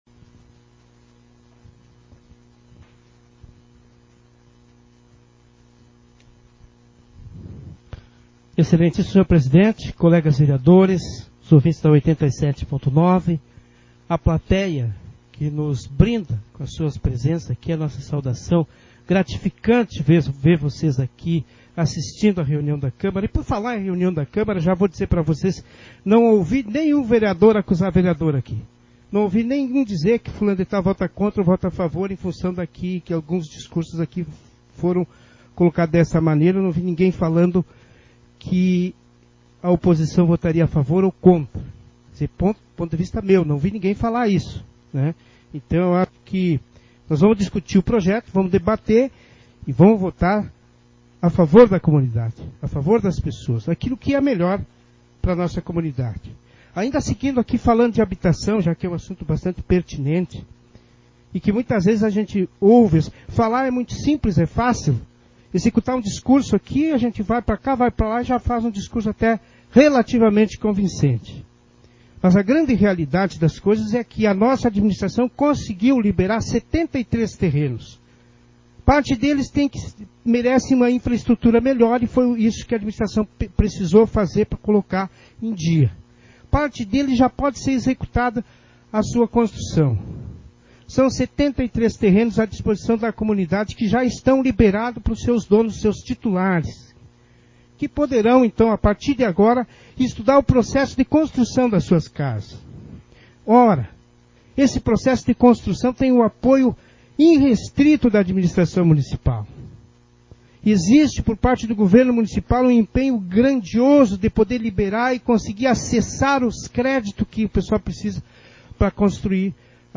Sessão Ordinária 30/2023